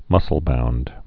mus·cle·bound also mus·cle-bound
(mŭsəl-bound)